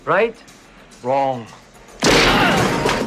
One of the most iconic Arnold Schwarzenegger quotes.
right_wrong_shooting.mp3